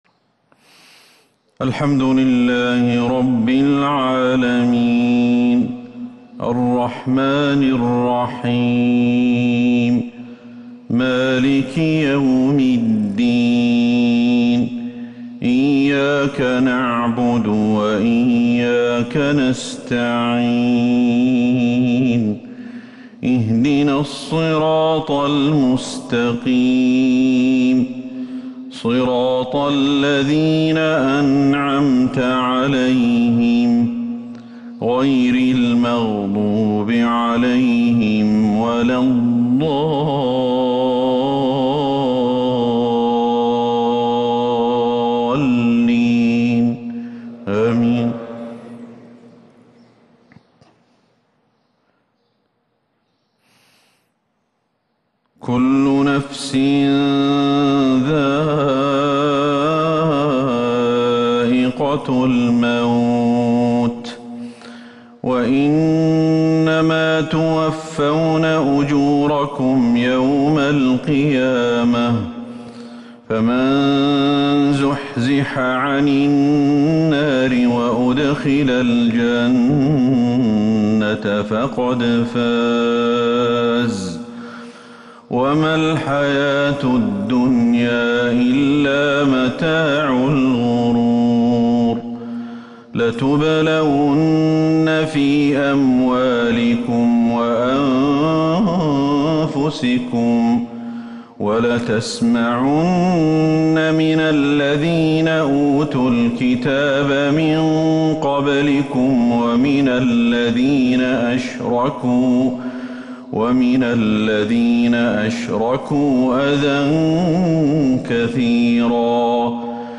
Fajr prayer from Surah Aal-i-Imraan 15/1/2021 > 1442 هـ > الفروض